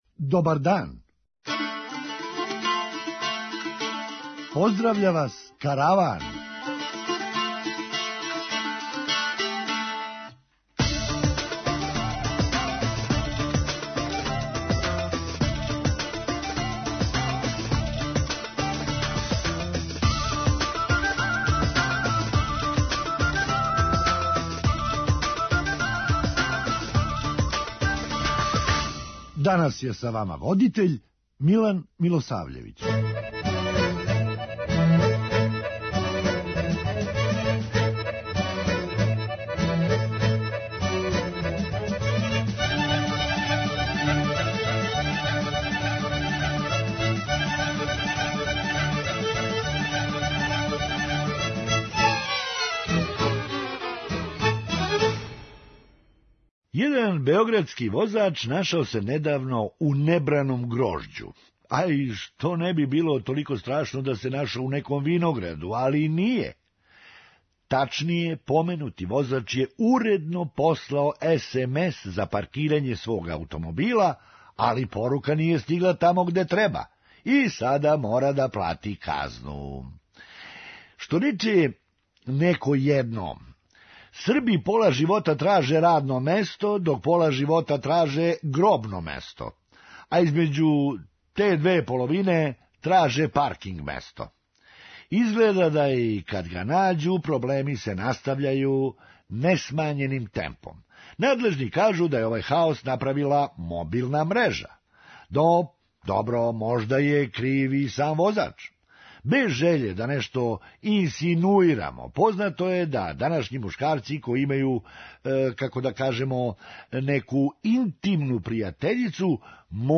Хумористичка емисија
Значи, још увек има наде да се на Каленић пијаци чује оно: ''Навали народе!'' преузми : 8.83 MB Караван Autor: Забавна редакција Радио Бeограда 1 Караван се креће ка својој дестинацији већ више од 50 година, увек добро натоварен актуелним хумором и изворним народним песмама.